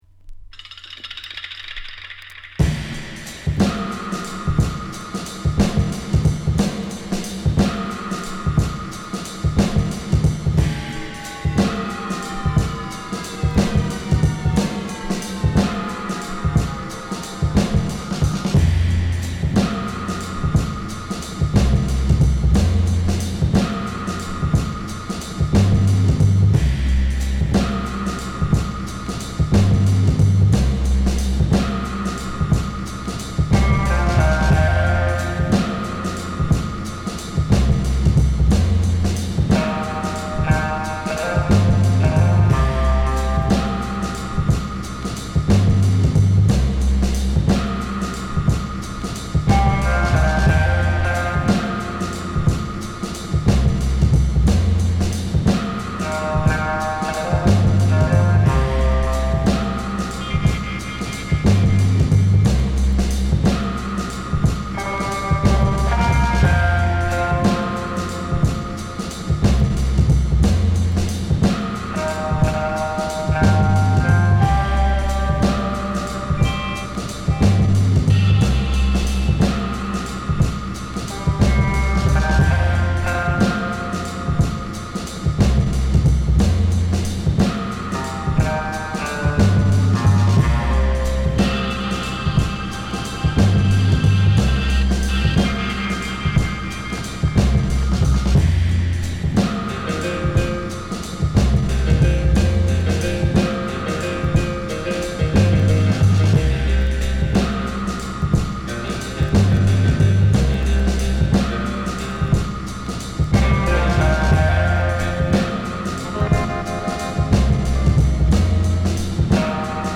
共に映像喚起のドープトラックです。